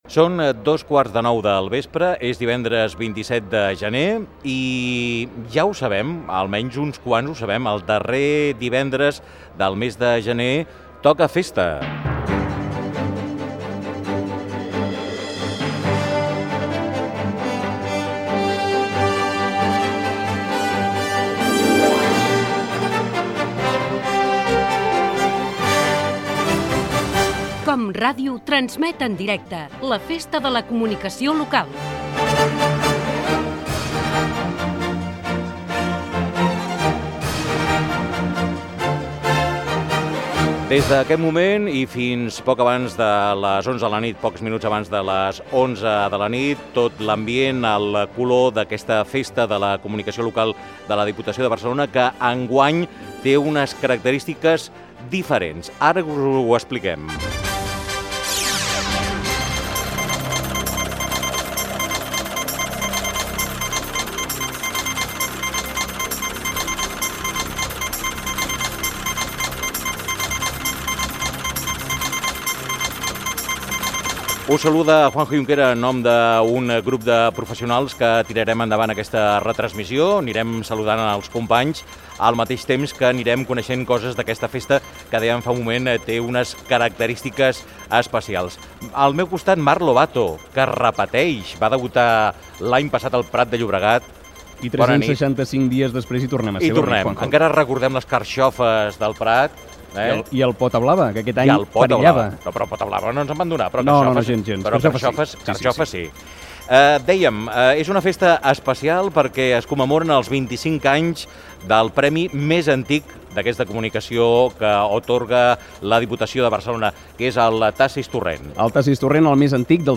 Transmissió dels Premis de Comunicació Local 2005 de la Diputació de Barcelona, des de les Drassanes de Barcelona. Hora, data, careta del programa, presentació, equip, 25 anys del premi Tasis-Torrent, les Drassanes de Barcelona, ambient de l'acte
Informatiu